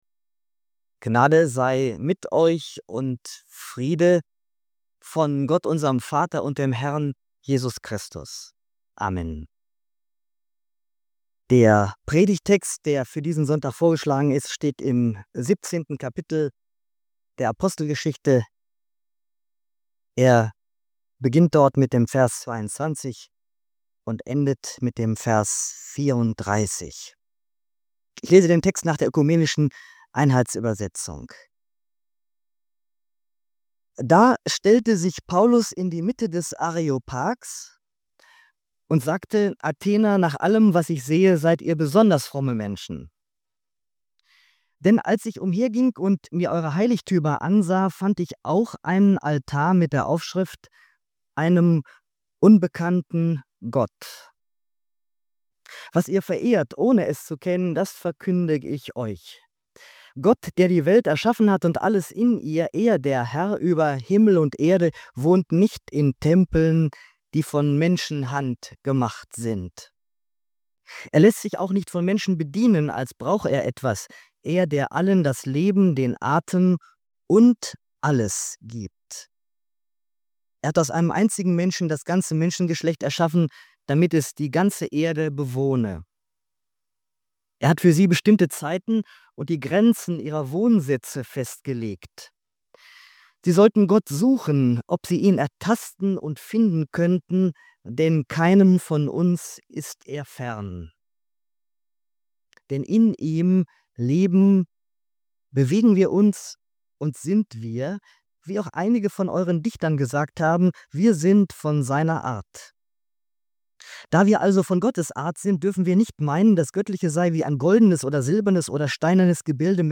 Die Predigt beleuchtet die Spannung zwischen kultureller Offenheit und christlichem Bekenntnis: Was ist unverzichtbarer Kern des Glaubens, und wo dürfen wir anknüpfen? Paulus macht drei Punkte deutlich: Gott hat sich offenbart, Gott lässt sich finden – durch Umkehr, und Gott wird richten.